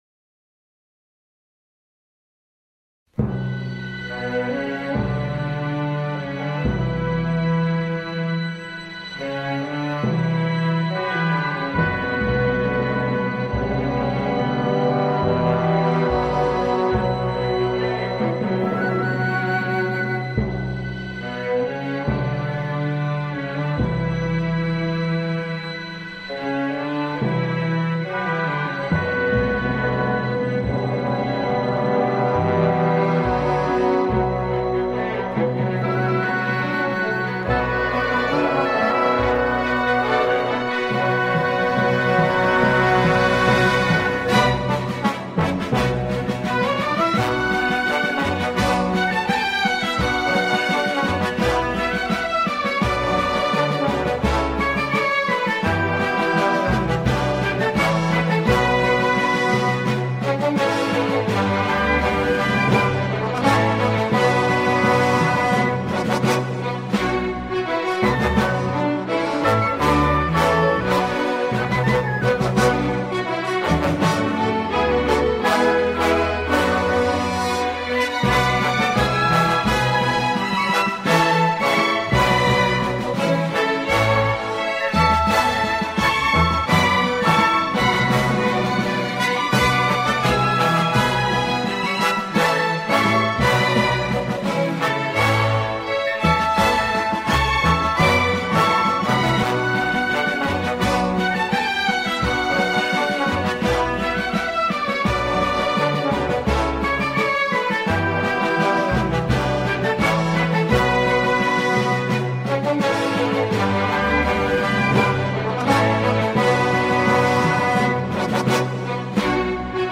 بی‌کلام